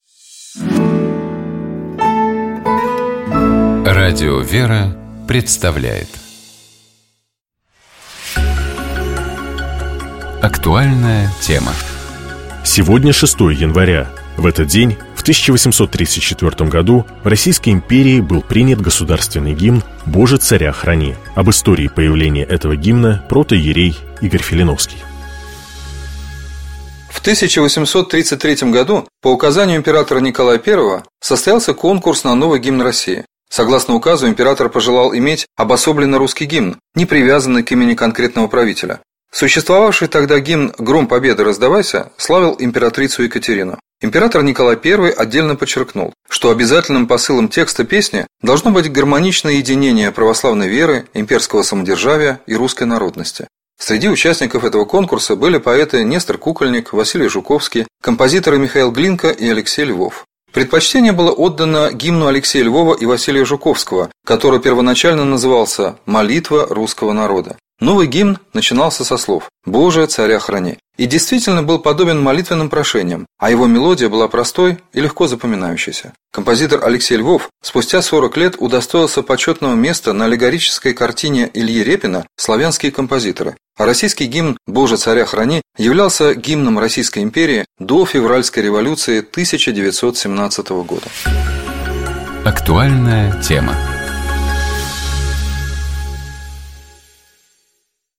Мы беседовали с епископом Переславским и Углическим Феоктистом.